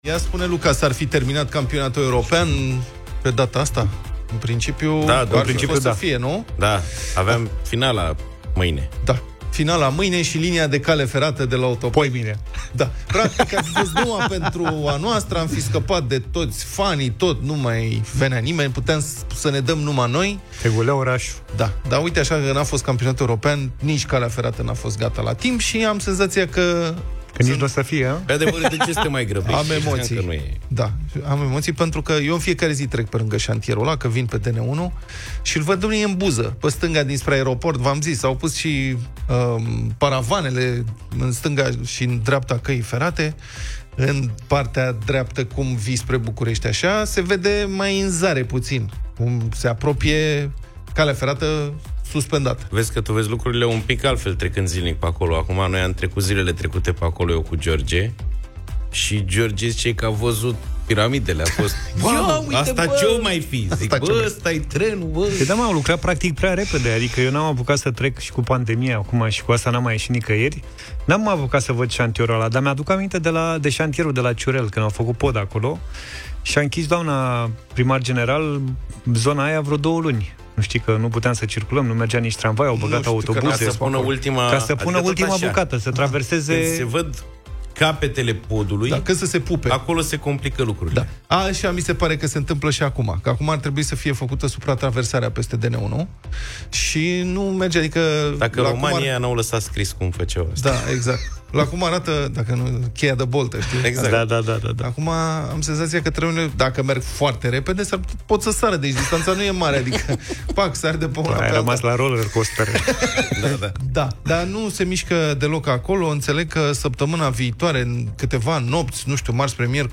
au vorbit despre acest subiect în Deșteptarea.